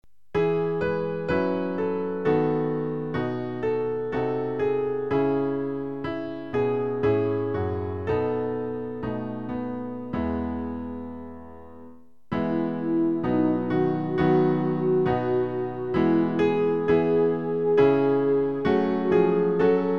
Klavier-Playback zur Begleitung der Gemeinde MP3 Download
Klavier-Playback